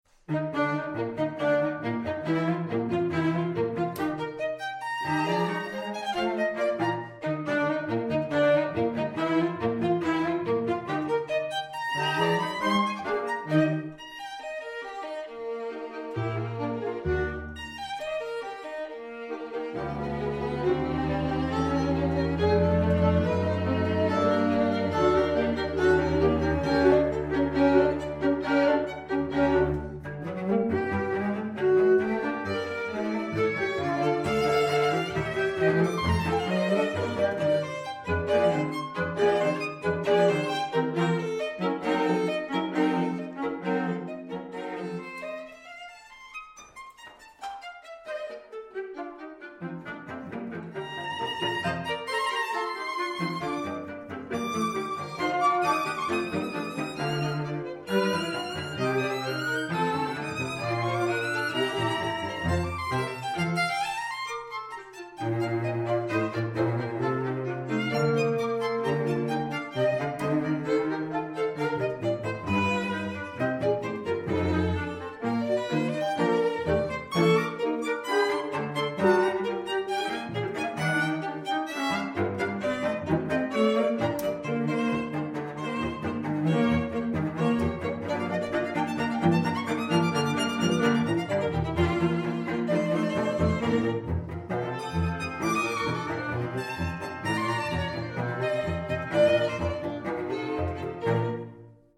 Soundbite 4th Movt
krommer-op24-2-movt4.mp3